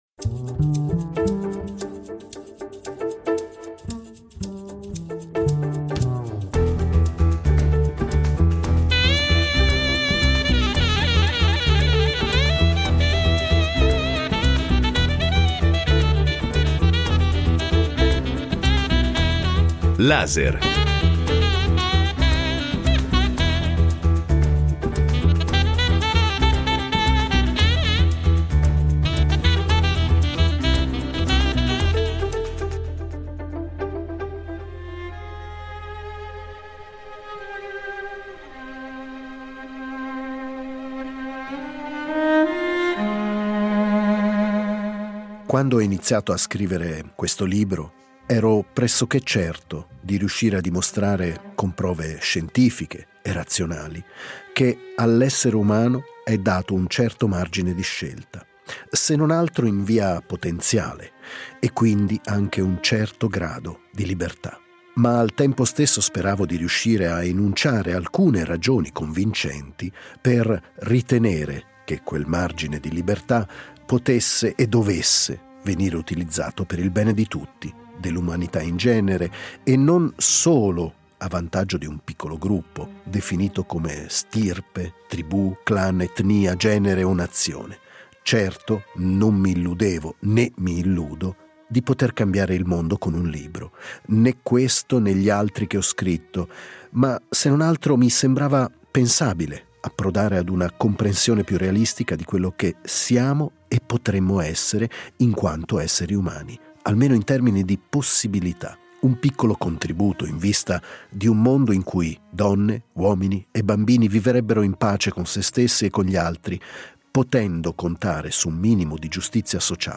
Riproponiamo l’intervista realizzata l’anno scorso allo scrittore e saggista Björn Larsson.
Ma pochi conoscono lo scienziato e saggista Björn Larsson che in perfetto italiano illustra in modo scientifico se e in che modo abbiamo margini di scelta e di libertà, e come fare in modo che l’intera umanità possa trarne beneficio.